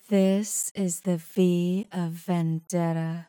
speech-female_hpsModel_sines
deterministic female harmonic model sinusoids sms-tools speech spoken sound effect free sound royalty free Memes